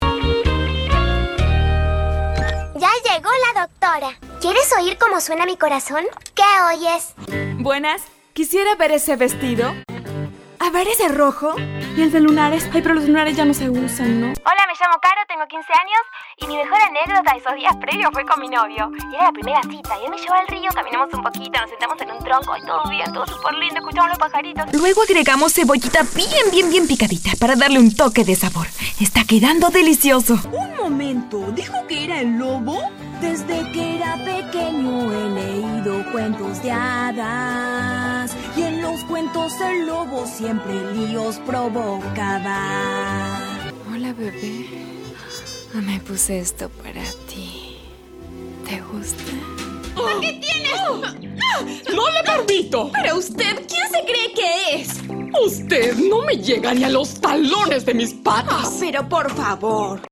Native speaker Female 20-30 lat
Nagranie lektorskie